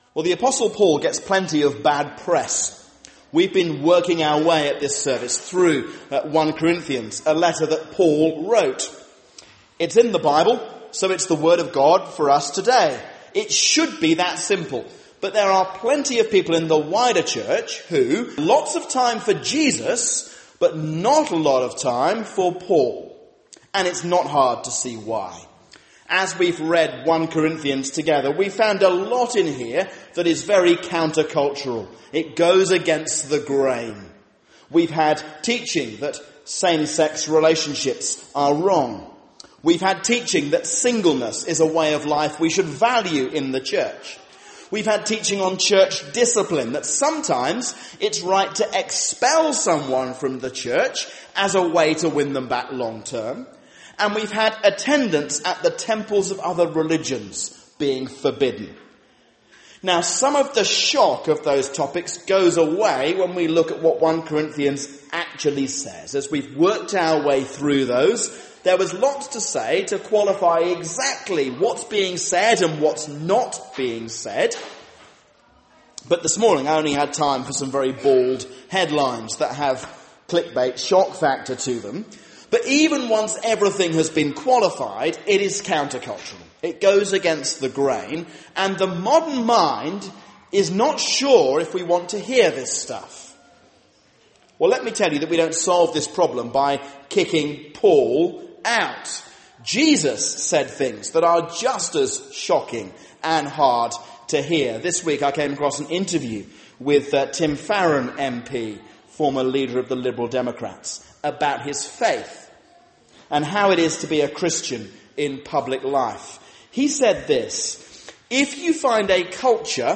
A sermon on 1 Corinthians 9:1-18